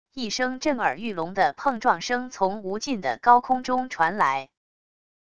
一声震耳欲聋的碰撞声从无尽的高空中传来wav音频